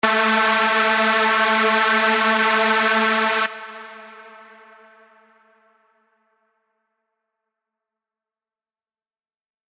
בעייה עם סאונד מלוכלך